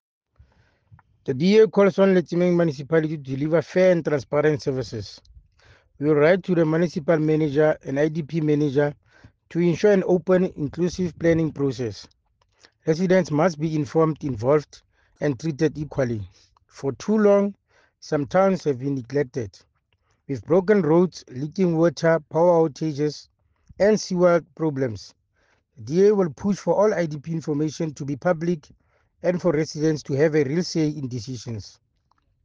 English soundbite by Cllr Thabo Nthapo,